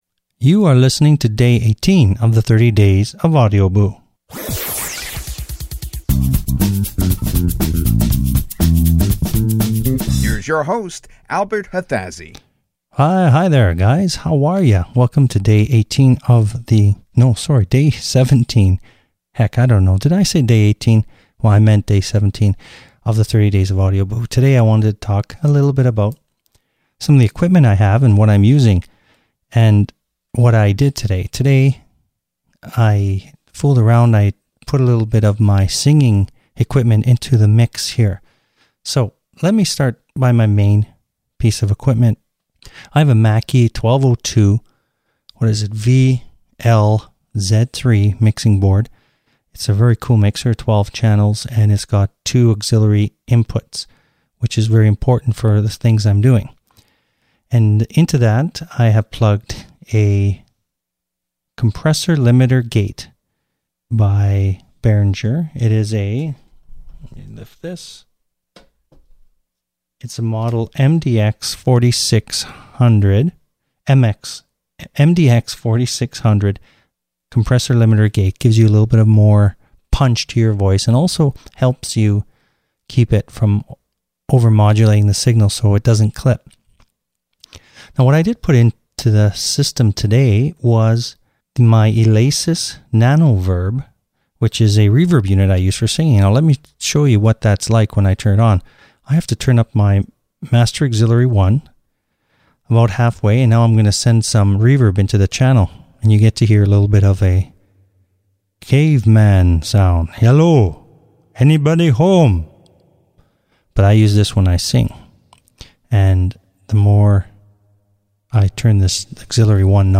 Have a listen to the Boo as I go over some of the gear I am using. And no...I didn't do any singing on the recording...consider yourself lucky.